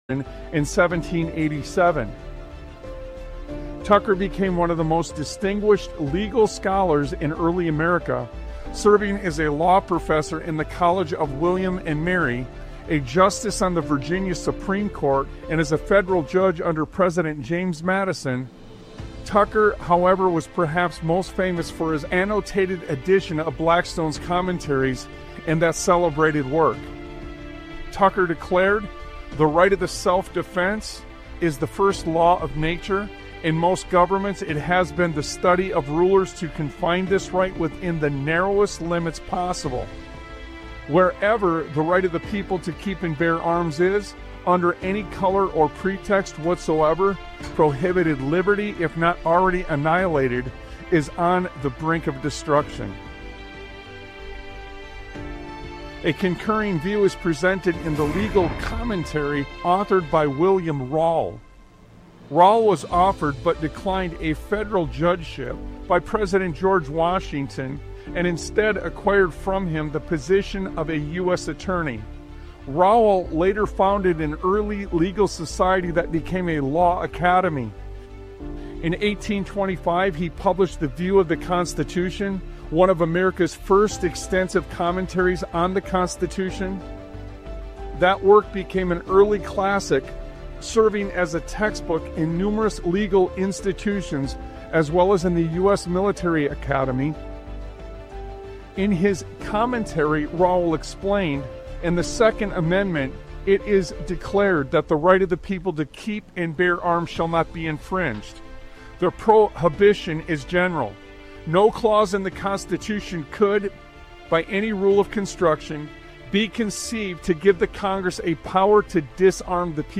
Talk Show Episode, Audio Podcast, Sons of Liberty Radio and Learn This, While You Still Can... on , show guests , about Learn This,While You Still Can, categorized as Education,History,Military,News,Politics & Government,Religion,Christianity,Society and Culture,Theory & Conspiracy